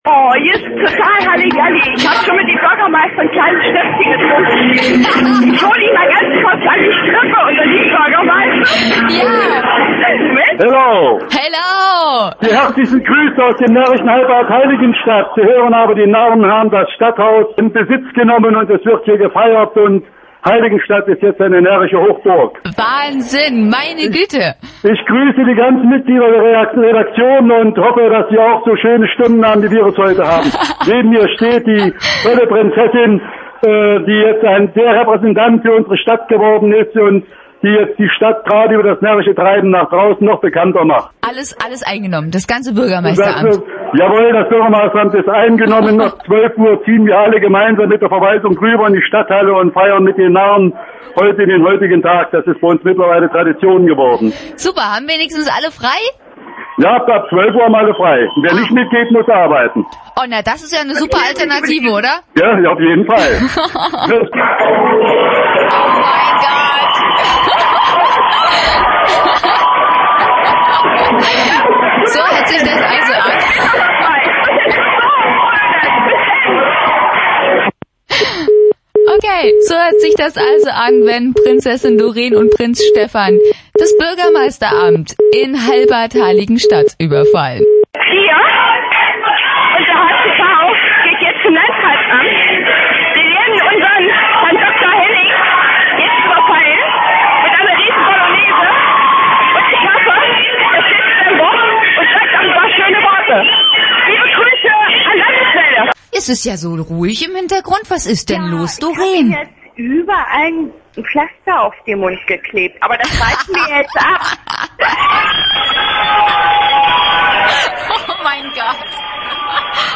7rof0902 Die "Thüringer Landeswelle" war an diesem Tag ebenfalls dabei. Von 10:00 bis 19:00 hat das Prinzenpaar 6 mal live berichtet.